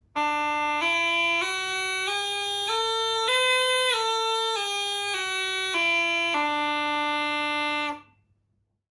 На этой странице собраны звуки жалейки — старинного русского инструмента с характерным тембром.
Звучание языческого духового инструмента ноты